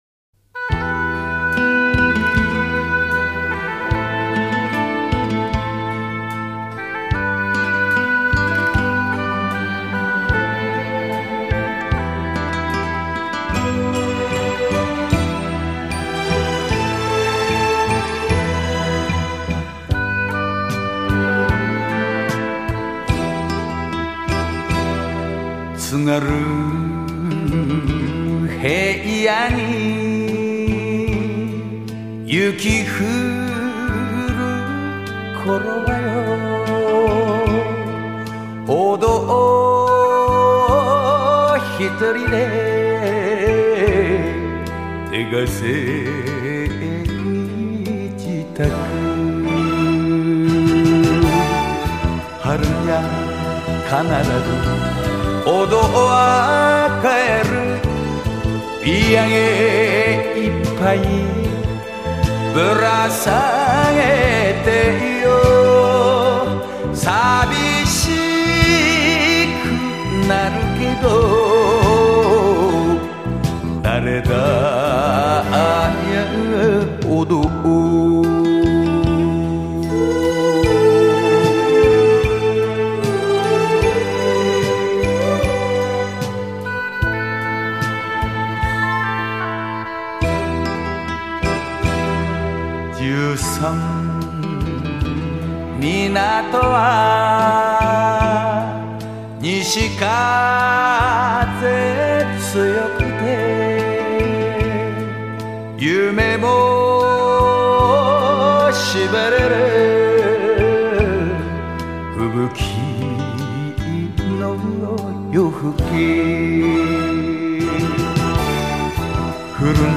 日本演歌